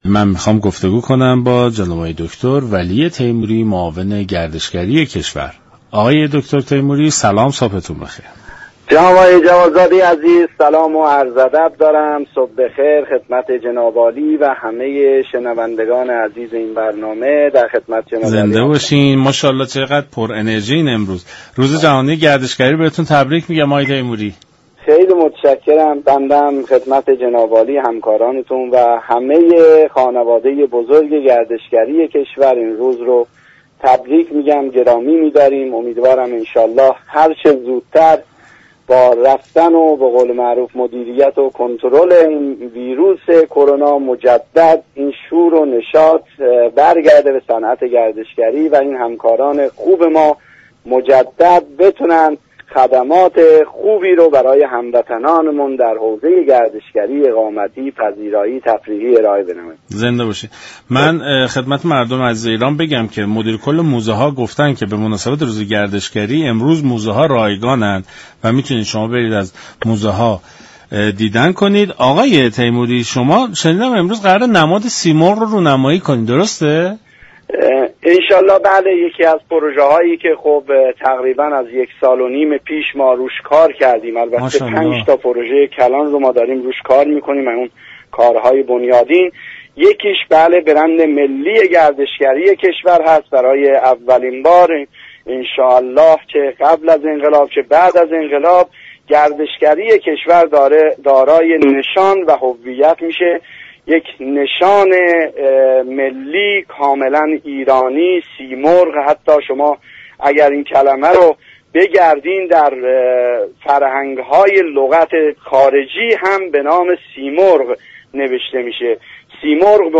به گزارش شبكه رادیویی ایران، دكتر ولی تیموری معاون گردشگری وزارت میراث فرهنگی، صنایع دستی و گردشگری در برنامه سلام صبح بخیر با تبریك روز جهانی گردشگری به خبر رونمایی از نماد سیمرغ اشاره كرد و گفت: نماد سیمرغ كه نشان ملی گردشگری ایران است و با نظر جمعی از متخصصان و كارشناسان طراحی شده امروز همراه با سند راهبردی گردشگری رونمایی می شود.